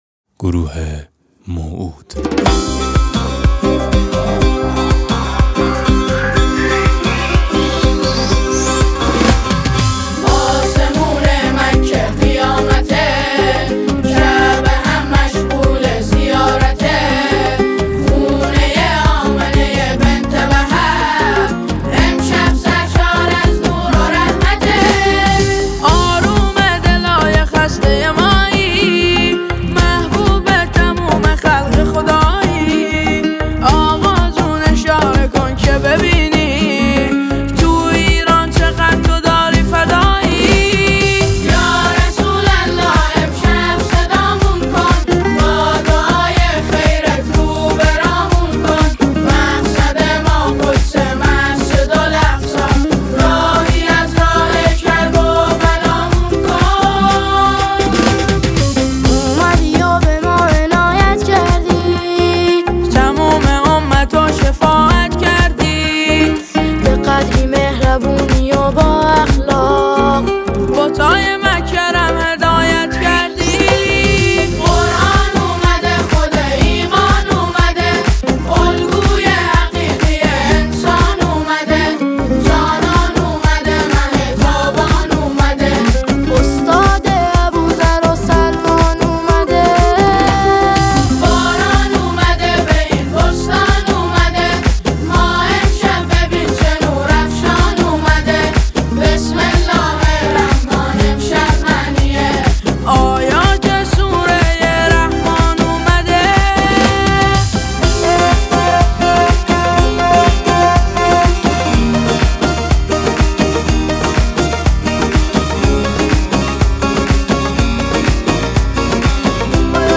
سرودی است معنوی و پرشور
ژانر: سرود